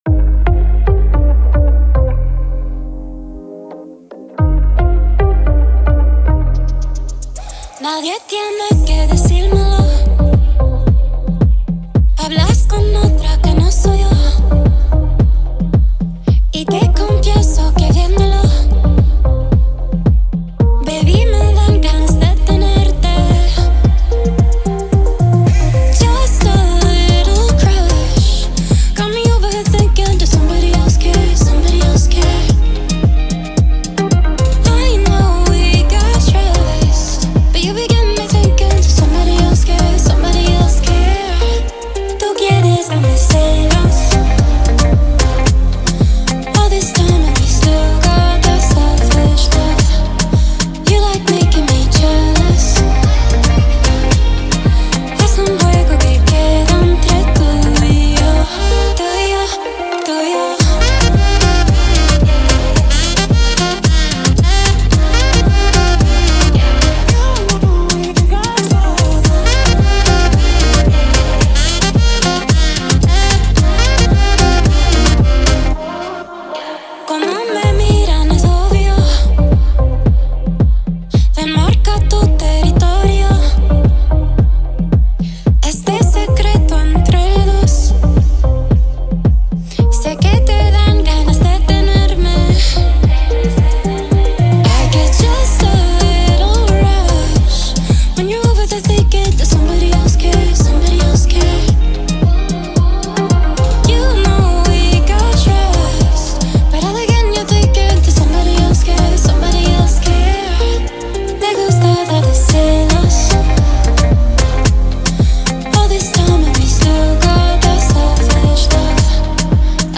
это захватывающая танцевальная композиция в жанре поп и EDM